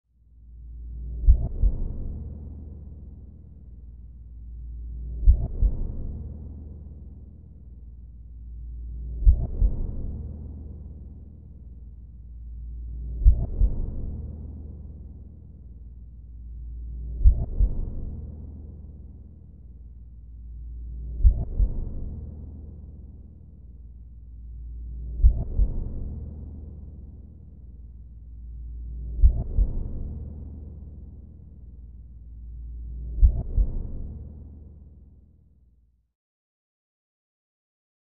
Heartbeat; Steady, With Eerie Air Suction And Reverb.